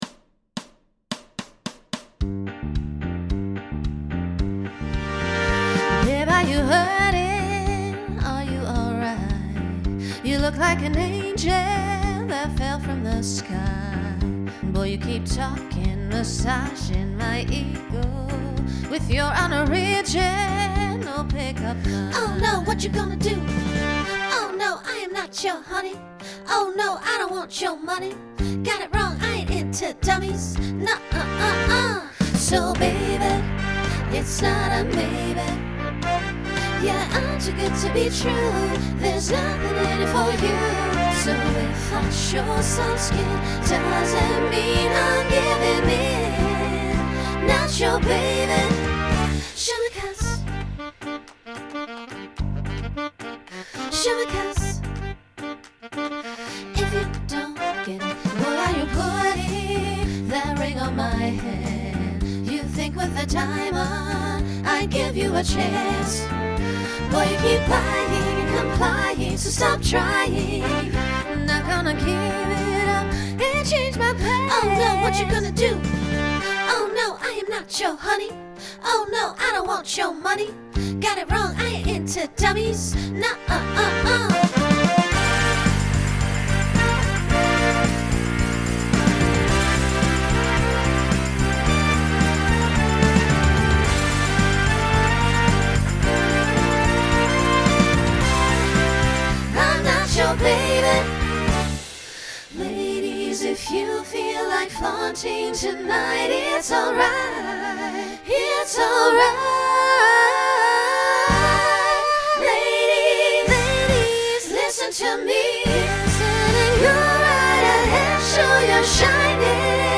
Genre Pop/Dance
Transition Voicing SSA